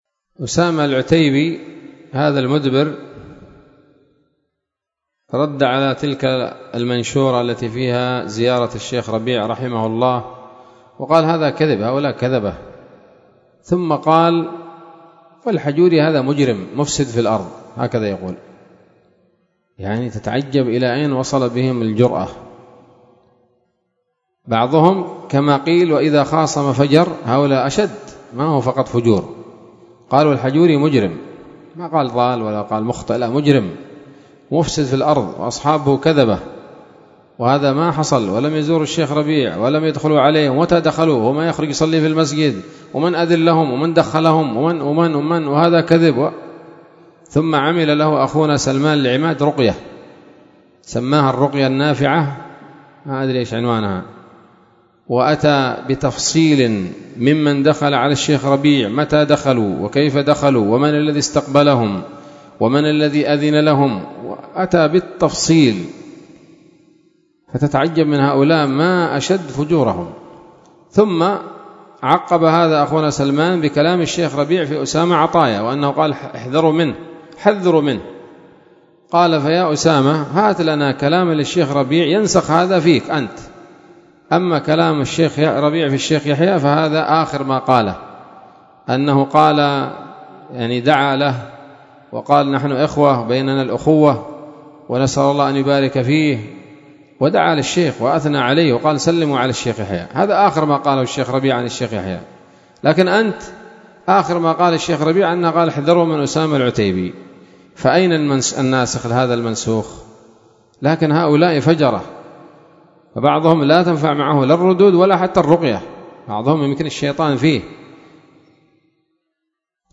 كلمة
بدار الحديث السلفية بصلاح الدين